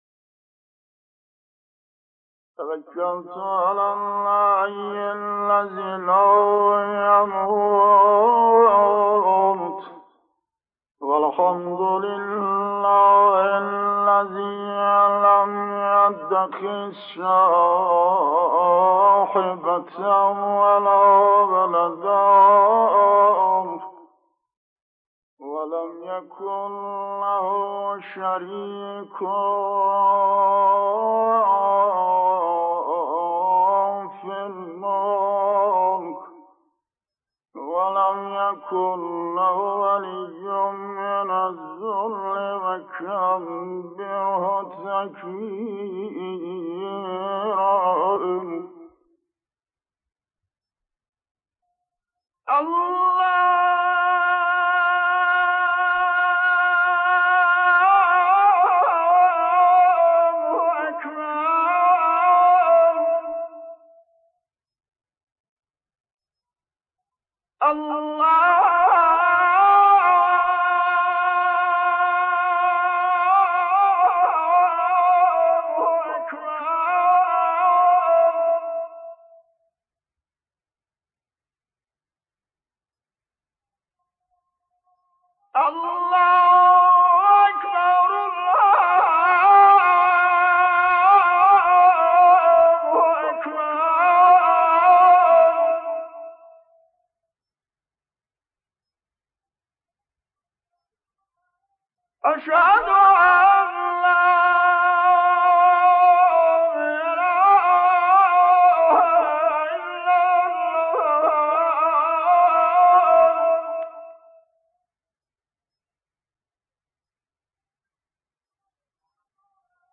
اذان سلیم موذن زاده.mp3
اذان-سلیم-موذن-زاده.mp3